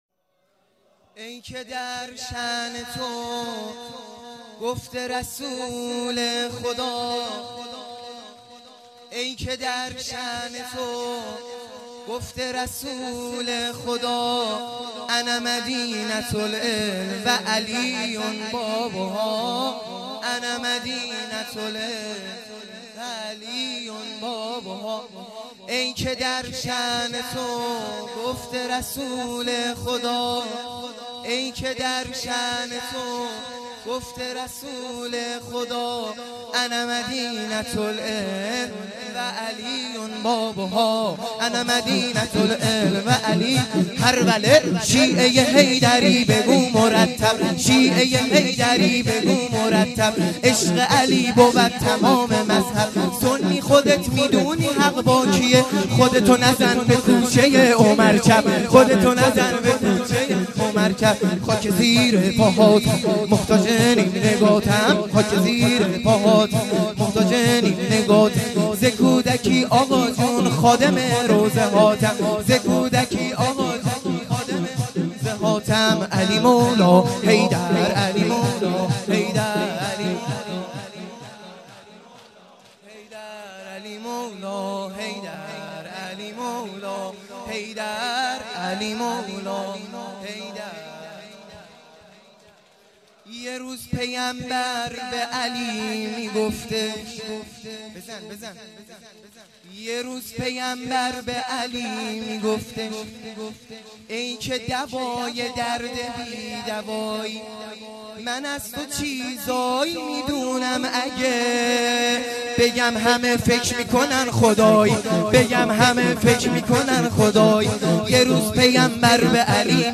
خیمه گاه - بیرق معظم محبین حضرت صاحب الزمان(عج) - شور | ای که در شان تو